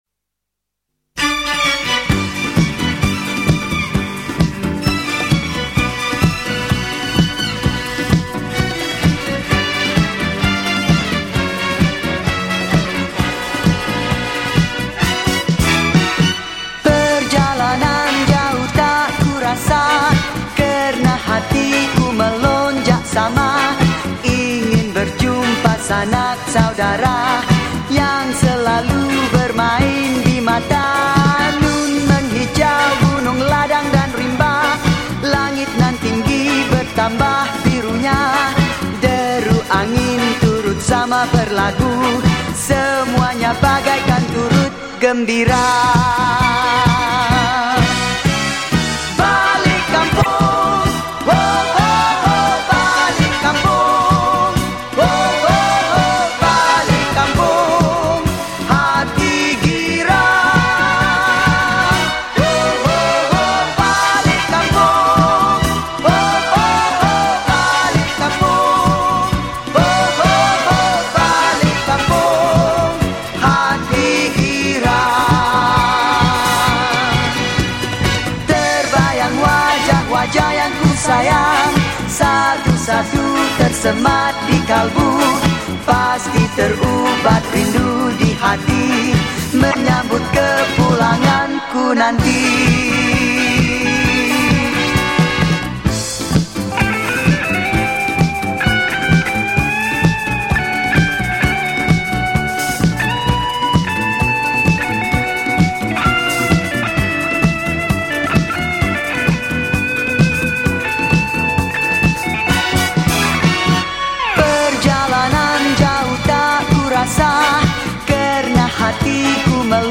Genre: Raya.